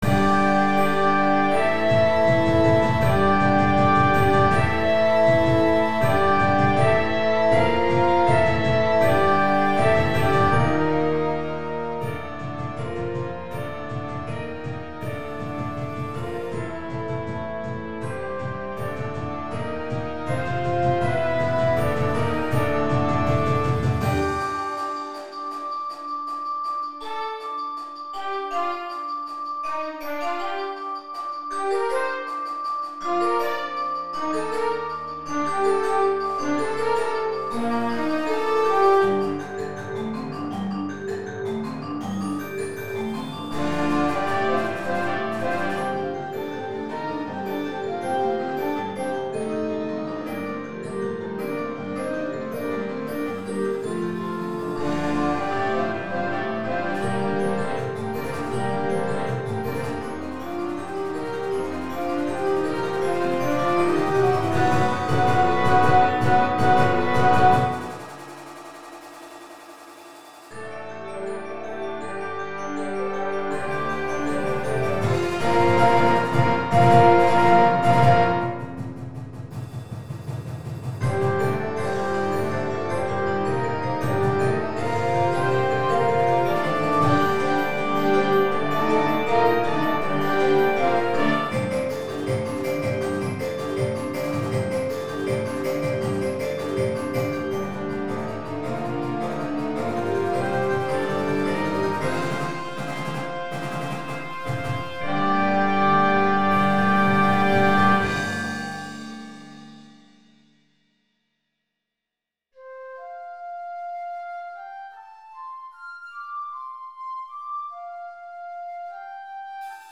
A mixture of folk melodies are brought together in